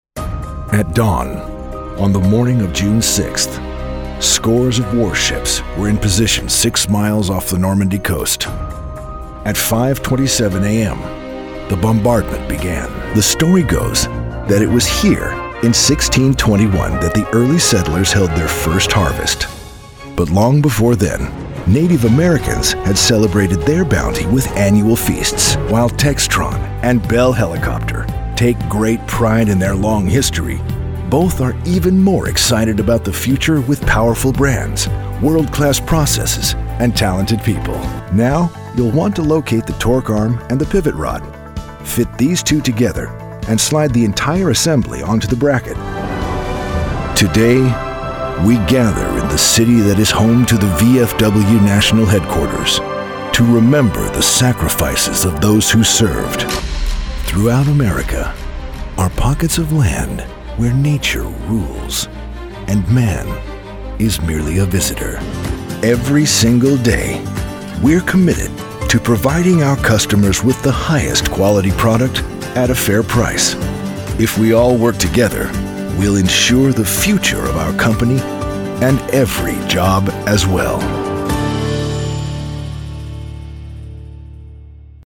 Narration_Documentary
His rugged baritone is well-traveled, trustworthy, believable and altogether gripping. It can be intimate and inviting, or intense and commanding.